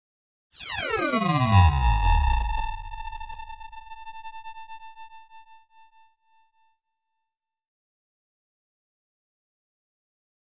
Digital High Frequency Digital Brake with Tremolo